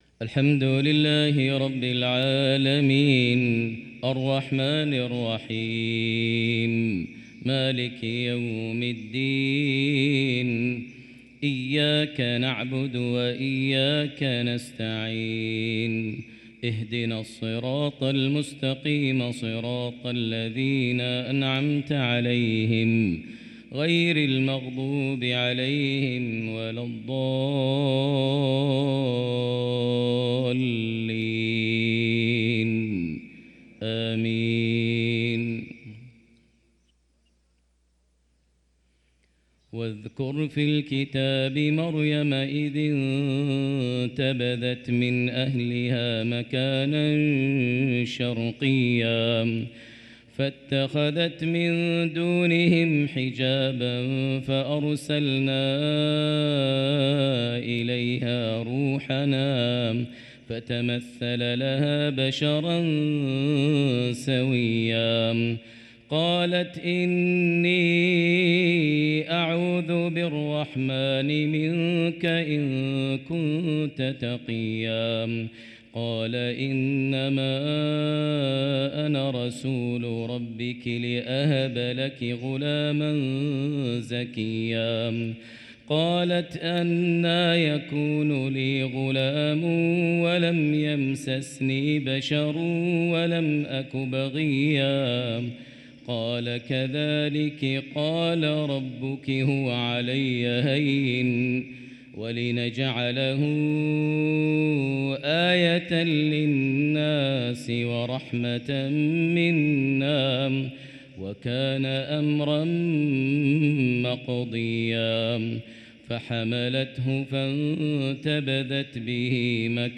صلاة الفجر للقارئ ماهر المعيقلي 2 رجب 1445 هـ
تِلَاوَات الْحَرَمَيْن .